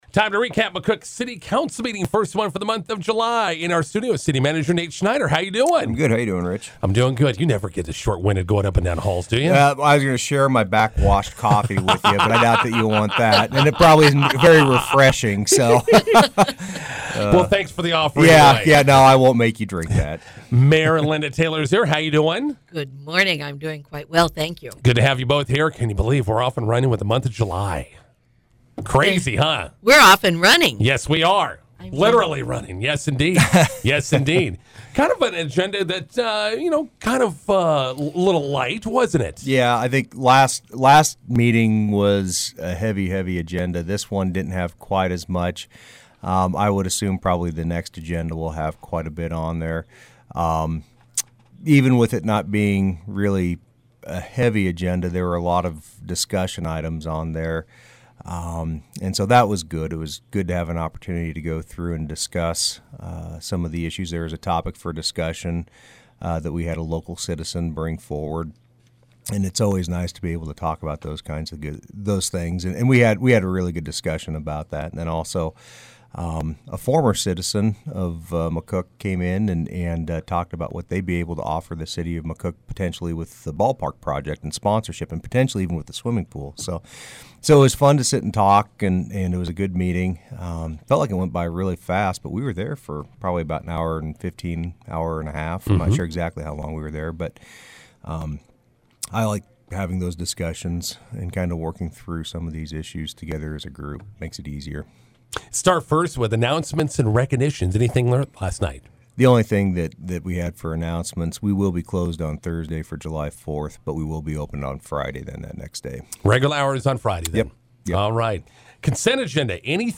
INTERVIEW: McCook City Council meeting recap with City Manager Nate Schneider and Mayor Linda Taylor